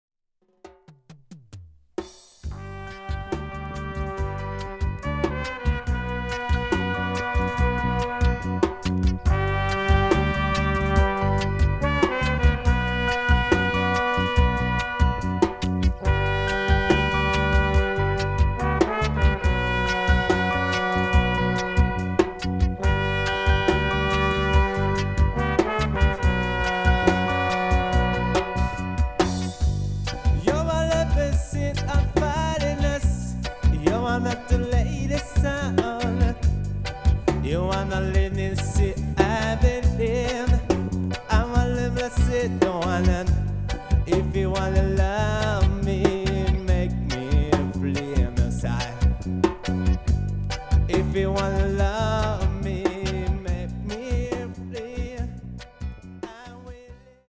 Recorded Phenomenon Studio
Tokyo Sep. 1983